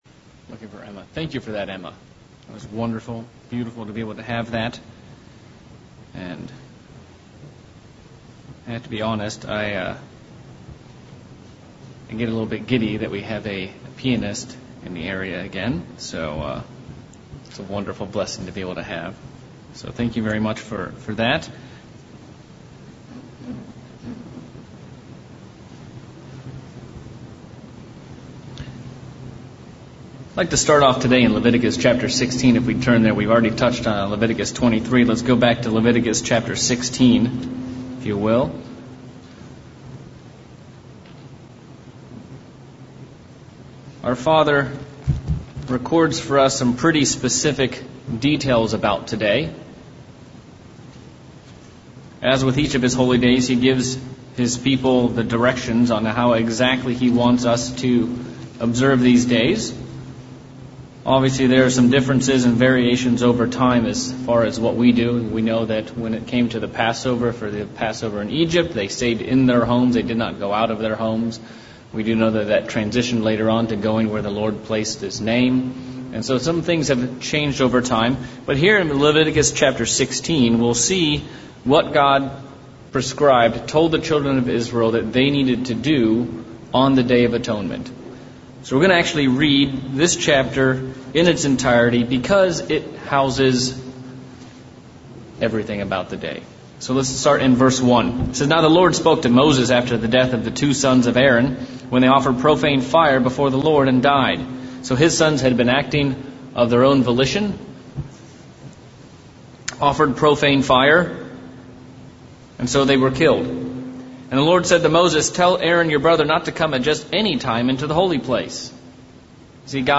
Day of Atonement sermon. While today is a fast today we can still partake of spiritual food.